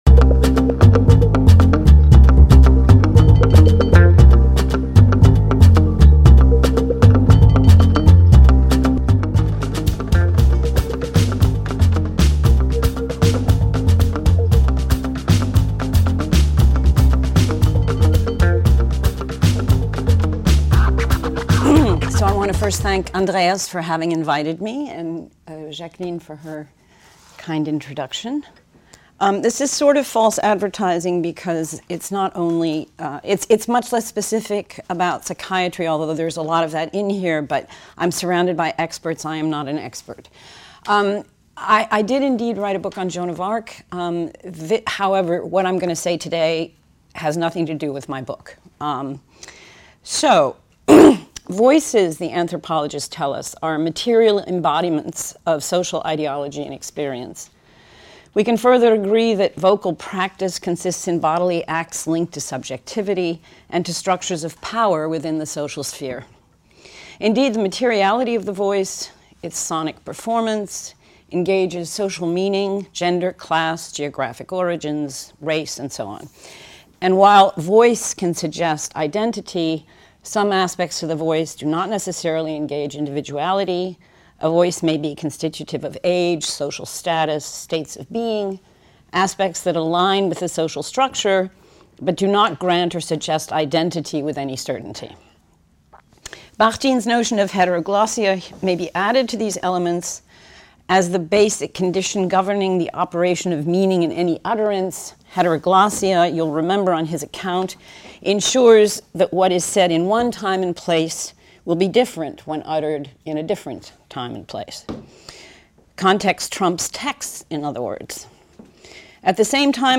Session 3 : Voix intérieures et langages imaginaires / Inner Voices and Imaginary Languages Conférence